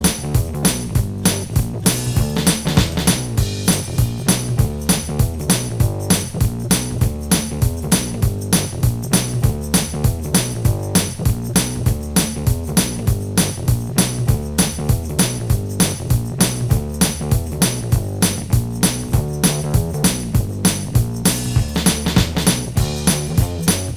Minus Guitars Rock 3:33 Buy £1.50